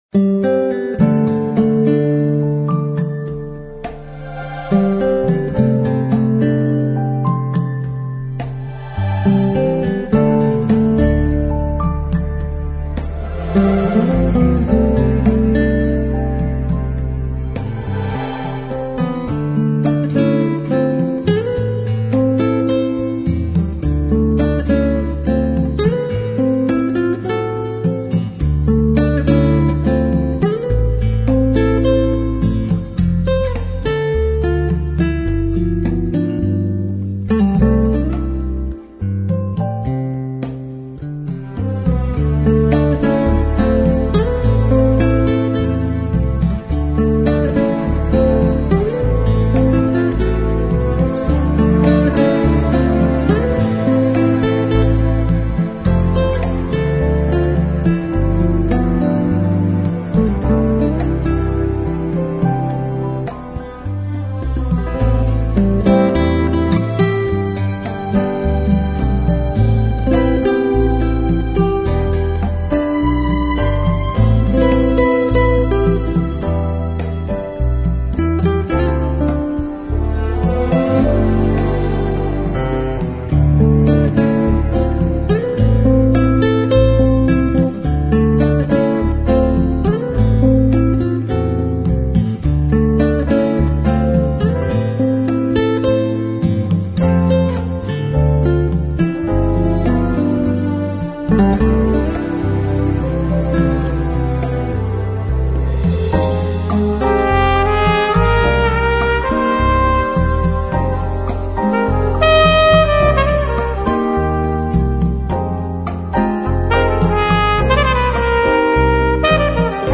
Bossa Nova Romance